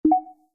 HB_BUTTON.mp3